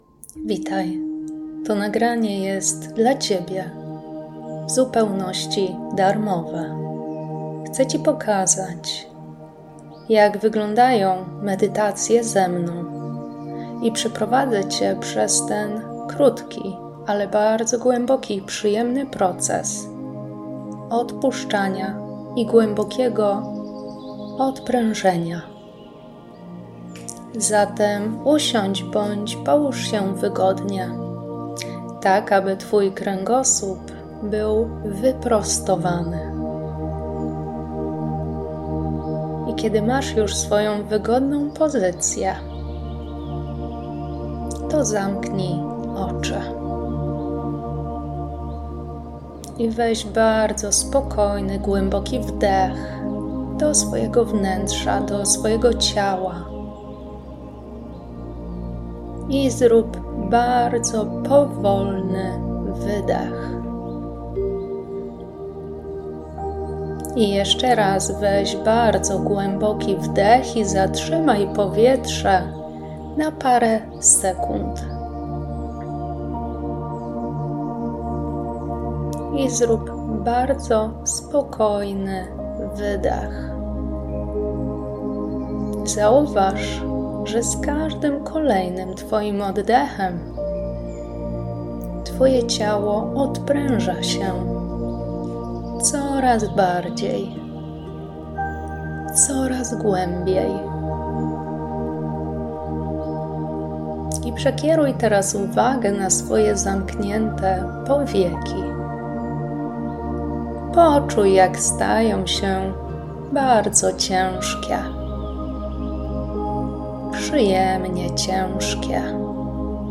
Darmowa medytacja - prezent dla Ciebie